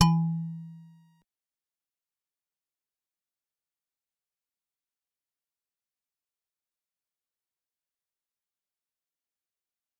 G_Musicbox-E3-pp.wav